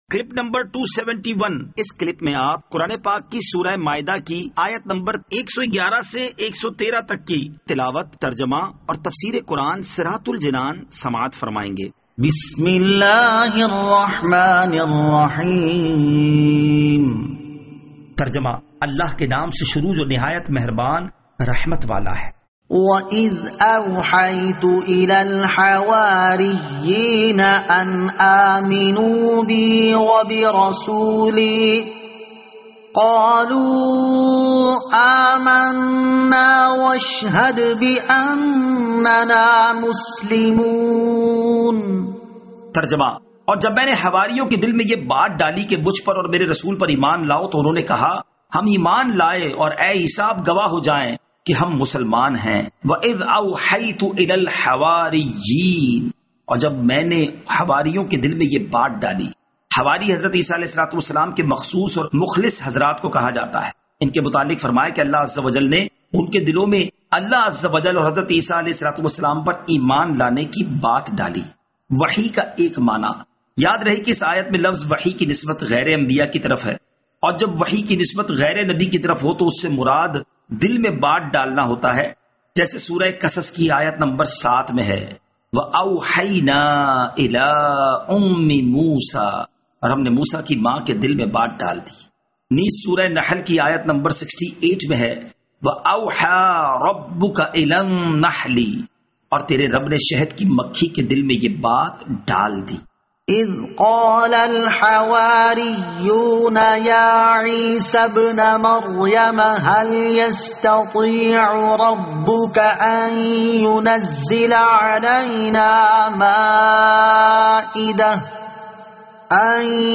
Surah Al-Maidah Ayat 111 To 113 Tilawat , Tarjama , Tafseer